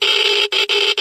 sound / items / geiger